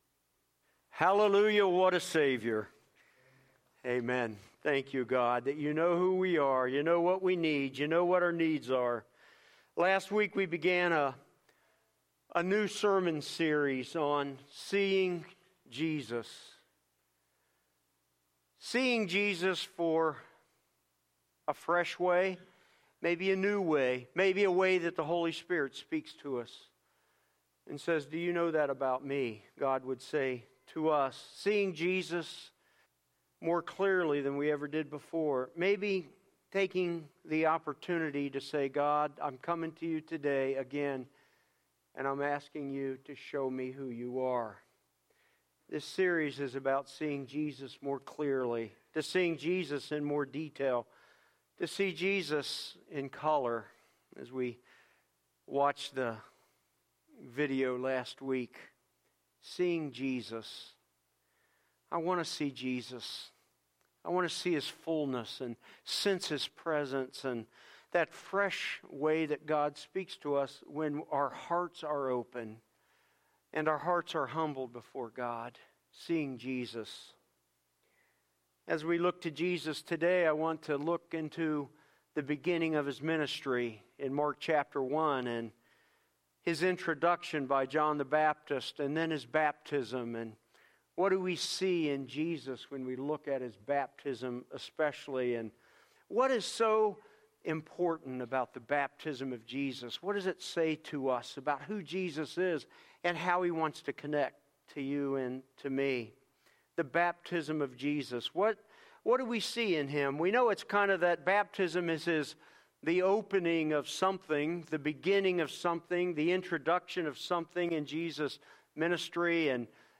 10:30AM Sunday The Wonder and Power of Jesus